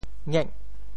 逆 部首拼音 部首 辶 总笔划 9 部外笔划 6 普通话 nì 潮州发音 潮州 ngêg8 文 gêh8 白 中文解释 逆〈動〉 (形聲。
ngek8.mp3